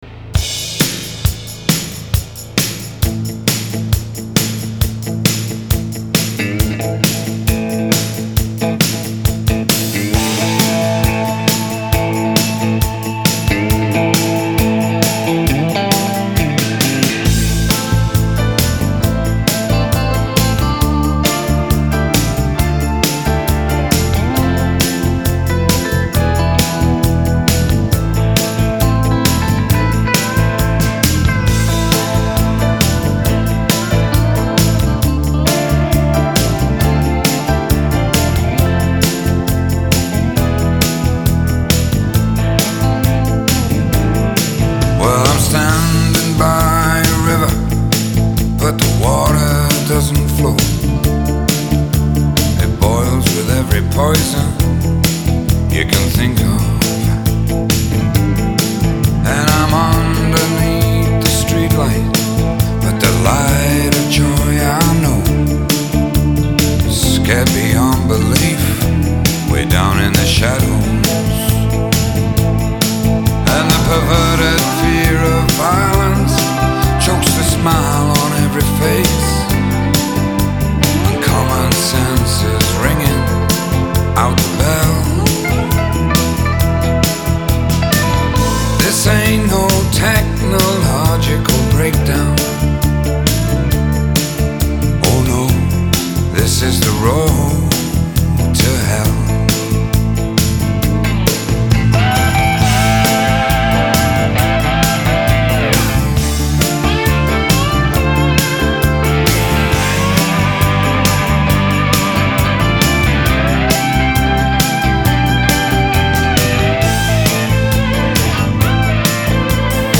سبک:بلوز راک